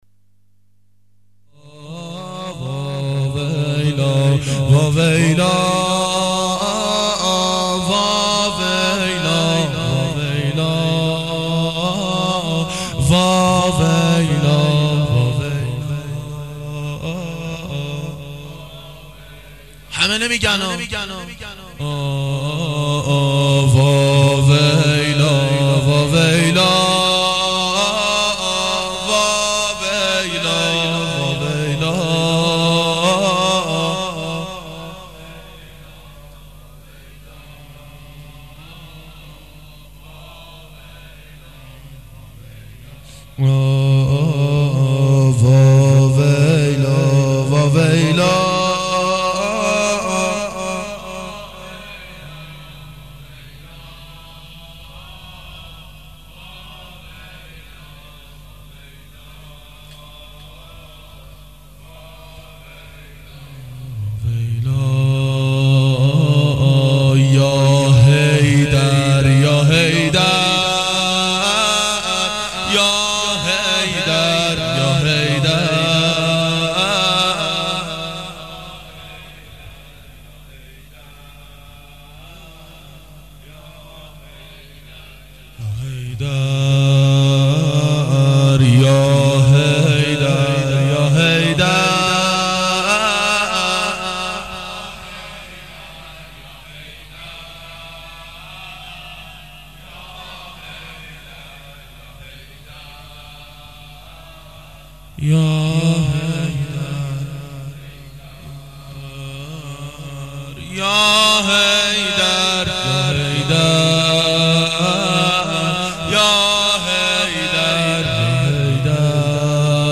گلچین مداحی